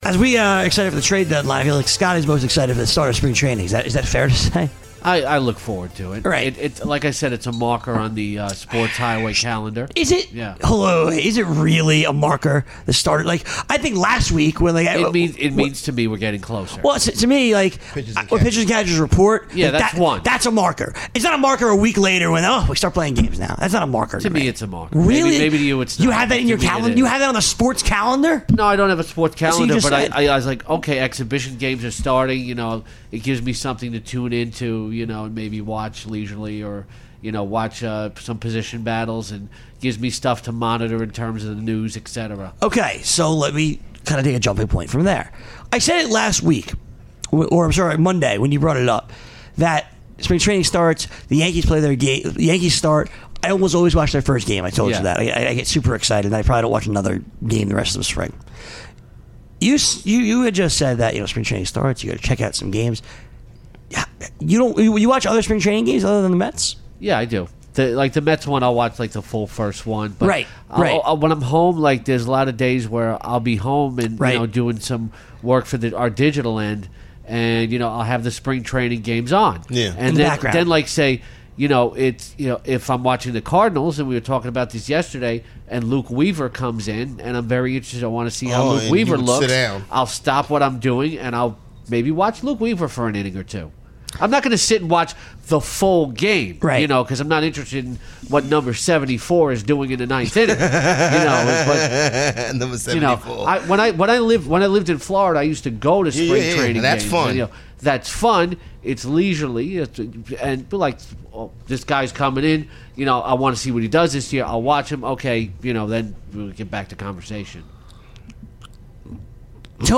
The RotoExperts discuss whether or not they get excited for Spring Training games, and if they watch, what they are on the look out for.